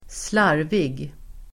Uttal: [²sl'ar:vig]